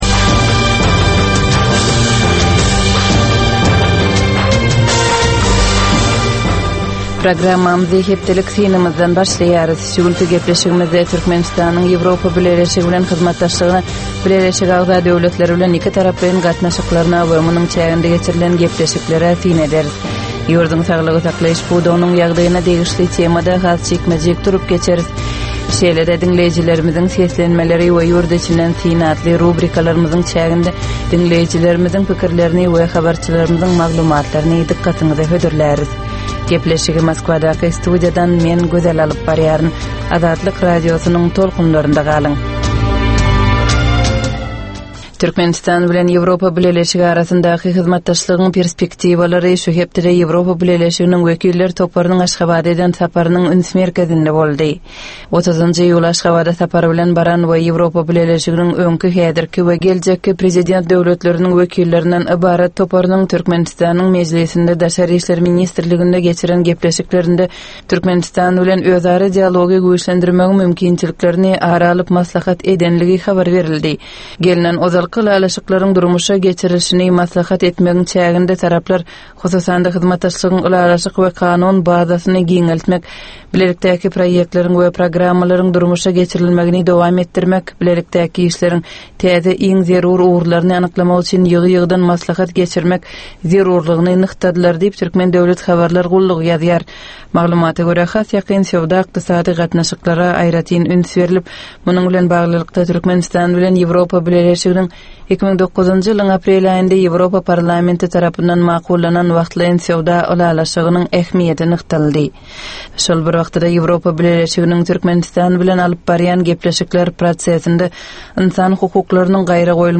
Tutus geçen bir hepdänin dowamynda Türkmenistanda we halkara arenasynda bolup geçen möhüm wakalara syn. 25 minutlyk bu ýörite programmanyn dowamynda hepdänin möhüm wakalary barada gysga synlar, analizler, makalalar, reportažlar, söhbetdeslikler we kommentariýalar berilýar.